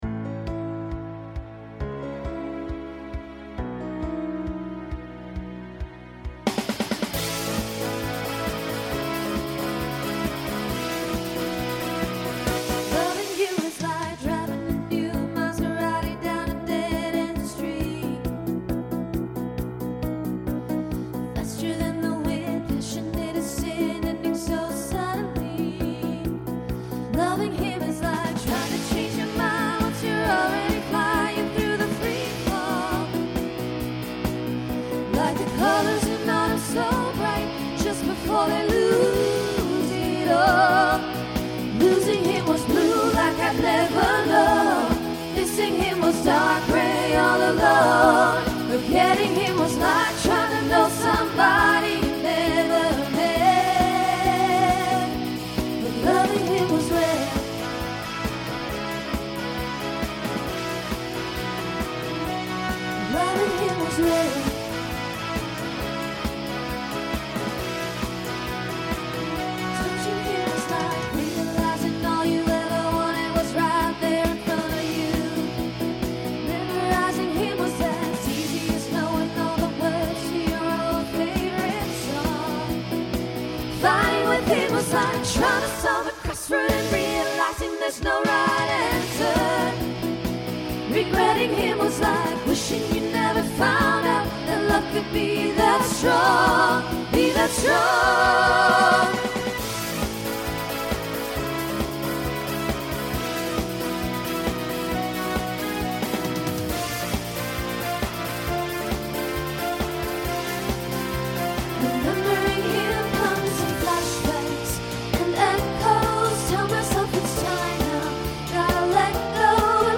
Voicing SSA Instrumental combo Genre Country , Rock